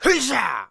attack_3.wav